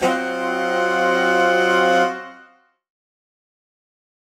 UC_HornSwellAlt_Cminb5.wav